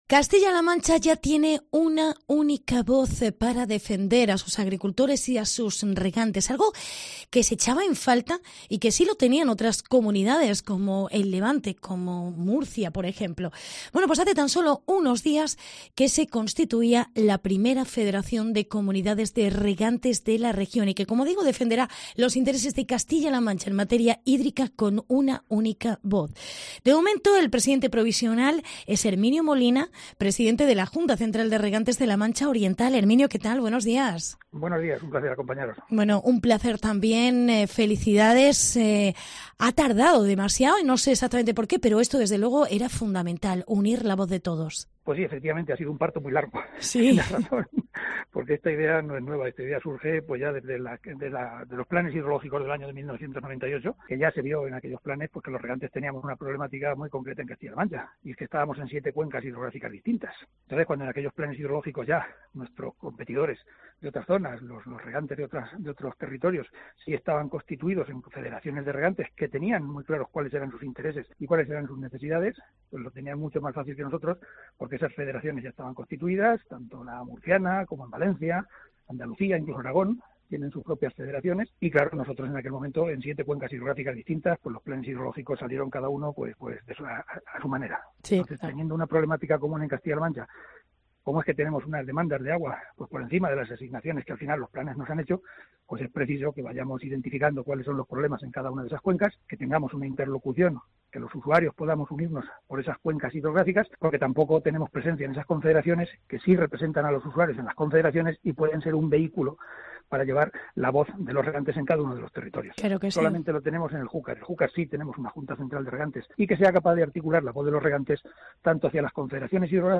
Después de 20 años se crea la Federación de Regantes de CLM.